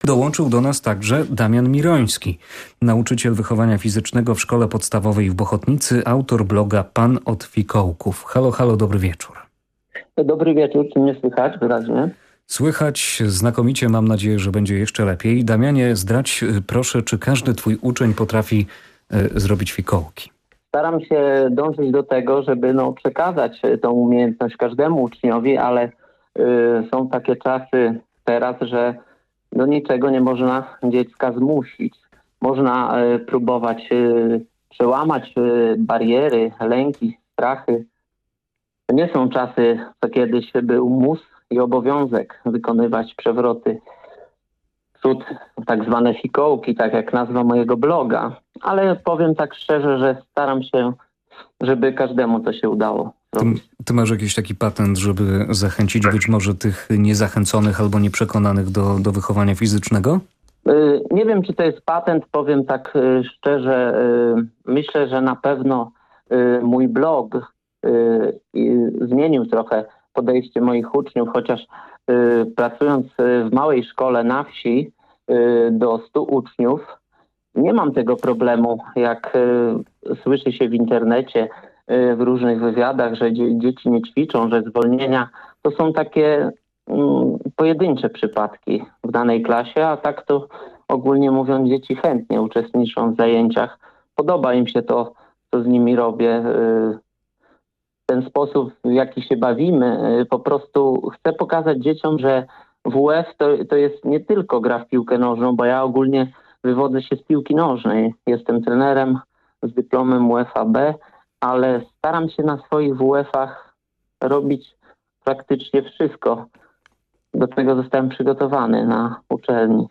Jeśli chcielibyście posłuchać moją wypowiedź, wrzucam nagranie z audycji do pobrania i zapraszam serdecznie do odsłuchania.